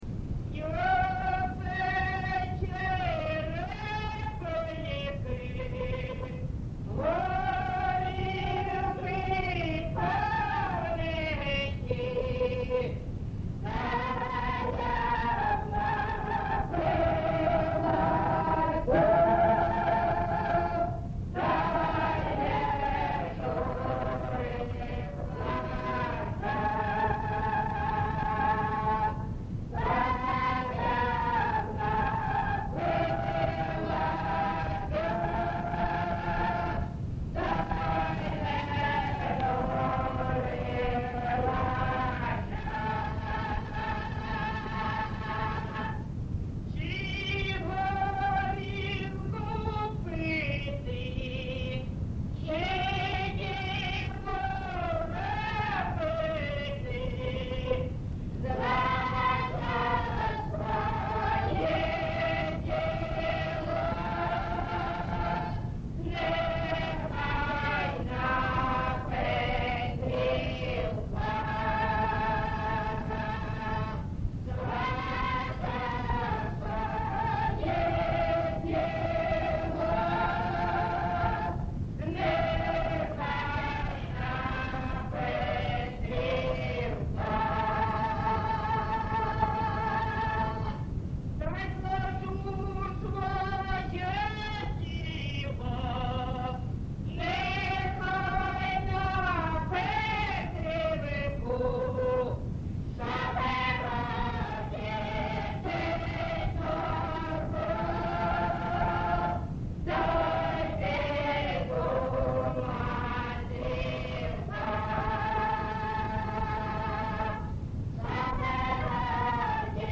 ЖанрПісні з особистого та родинного життя
Місце записус. Семенівка, Краматорський район, Донецька обл., Україна, Слобожанщина